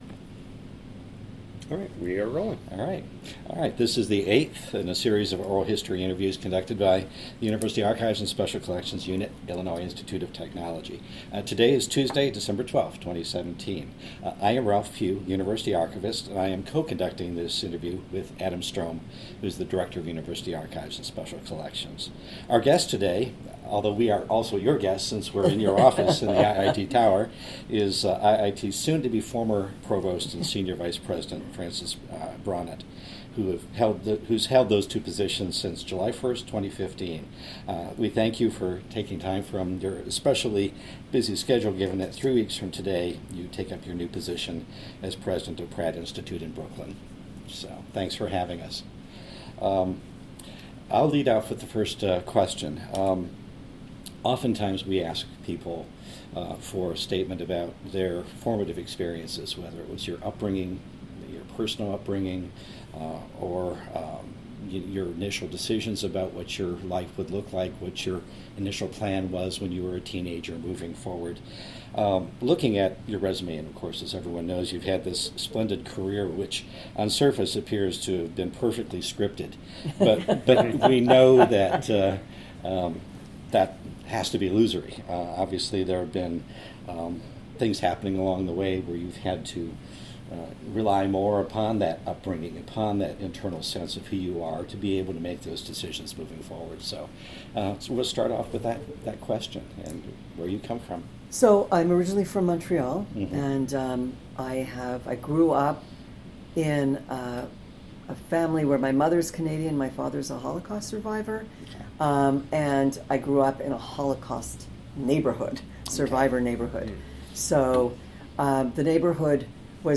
Type Interview